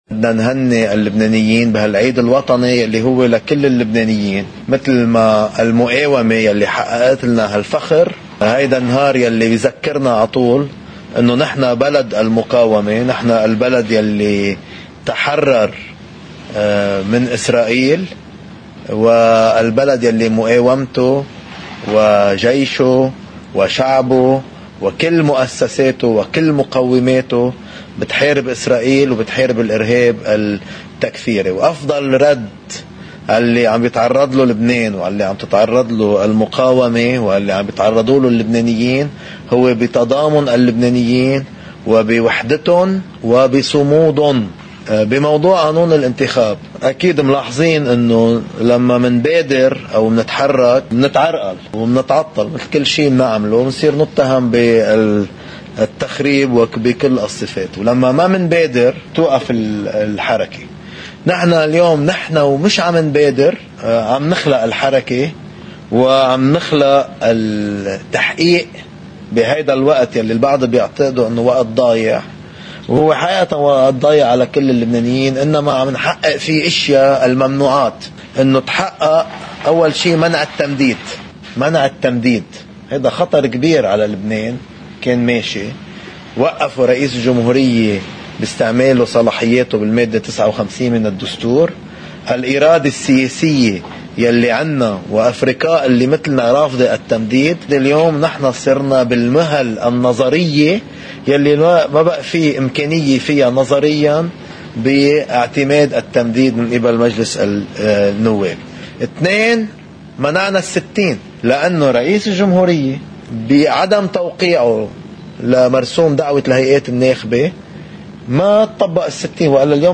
مقتطف من حديث رئيس التيار الوطني الحرّ جبران باسيل إثر اجتماع تكتّل التغيير والإصلاح في الرابية: